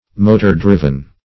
Search Result for " motor-driven" : The Collaborative International Dictionary of English v.0.48: motor-driven \mo`tor-driv`en\, a. (Mach.)